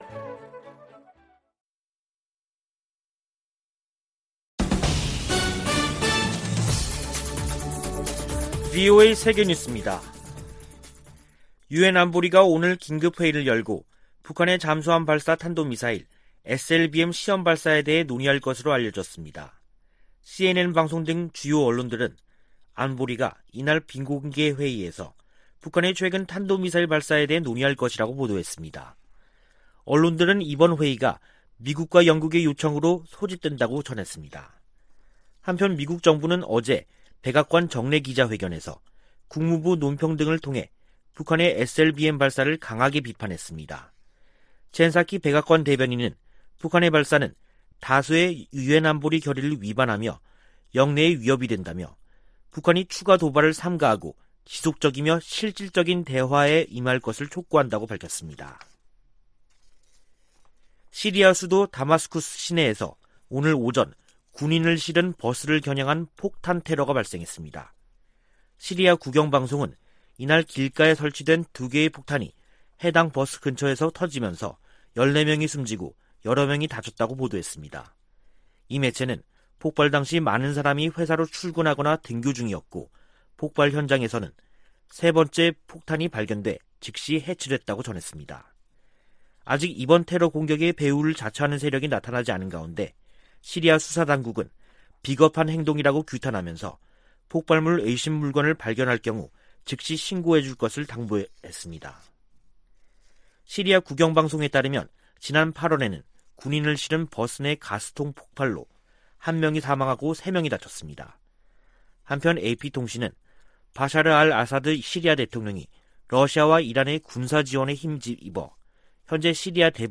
VOA 한국어 간판 뉴스 프로그램 '뉴스 투데이', 2021년 10월 20일 2부 방송입니다. 미국 정부는 북한의 탄도미사일 발사에 우려하며 조속히 대화에 나설 것을 촉구했습니다.